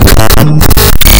Fragment_Glitch.mp3